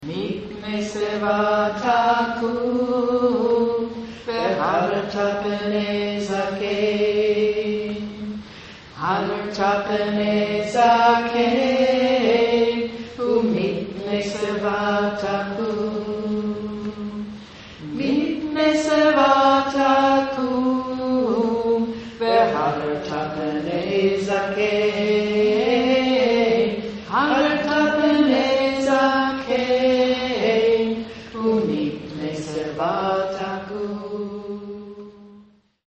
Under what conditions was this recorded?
For more than 15 years we met monthly in the Reutlinger Community Synagogue.